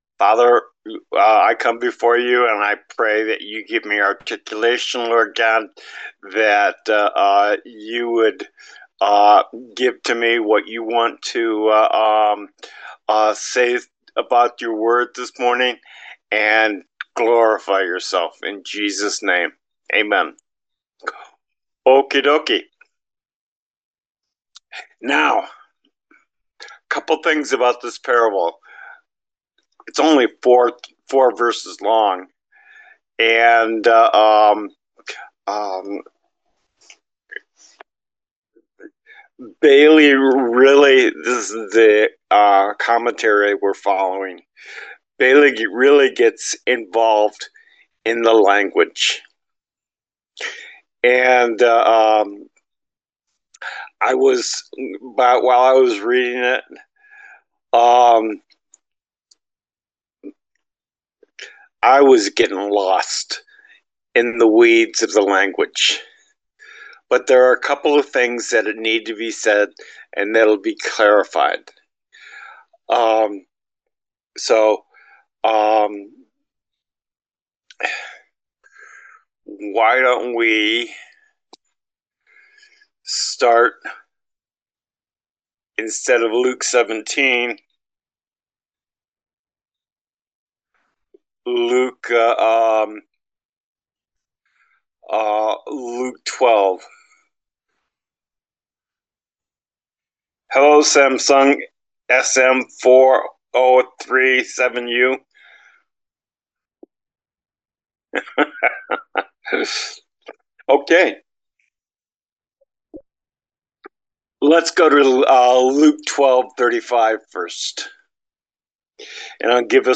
Thursday Bible Study: Luke 17:7-10
Service Type: Thursday Bible Study